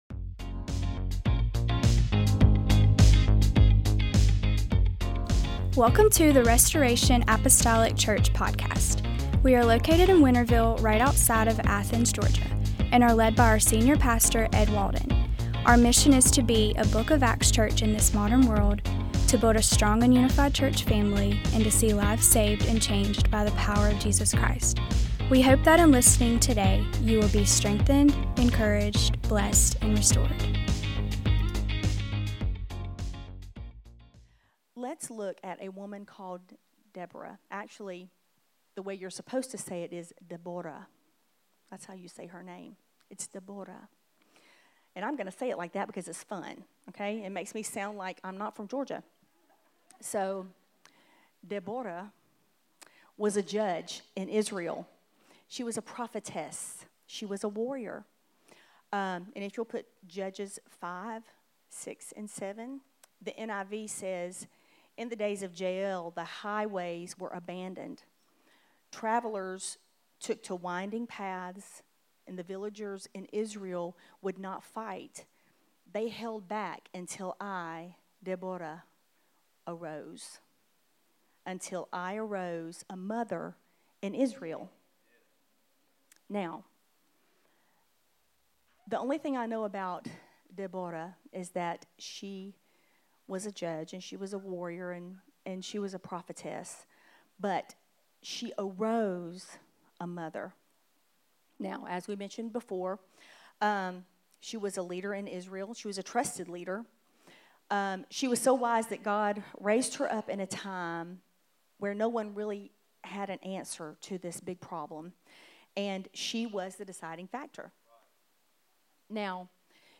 Mother's Day Sunday Service